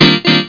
ChatRequest.mp3